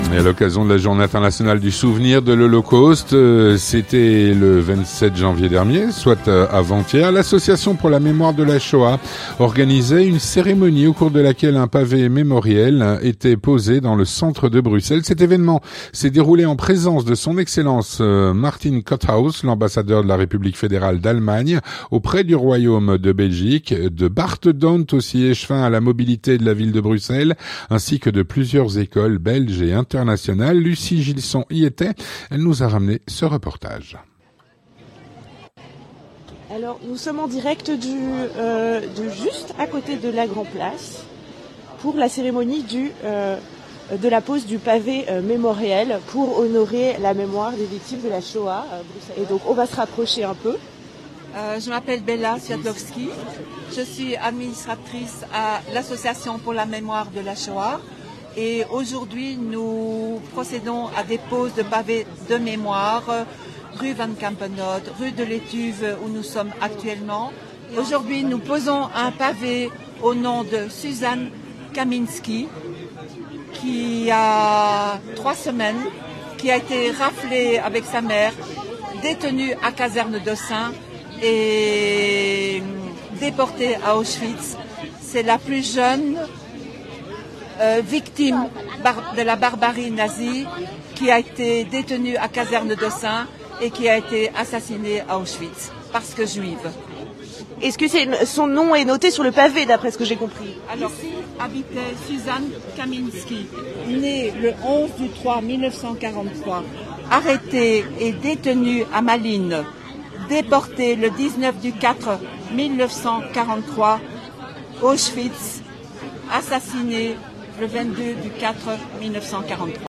A l’occasion de la journée internationale du souvenir de l'Holocauste, l’association pour la mémoire de la Shoah organisait une cérémonie au cours de laquelle un pavé mémoriel était posé dans le centre de Bruxelles.
Avec Son Excellence Martin Kotthaus, Ambassadeur de la République Fédérale d’Allemagne en Belgique, de Bart Dhondt, échevin à la mobilité de la ville de Bruxelles et de plusieurs écoles, belges et internationales.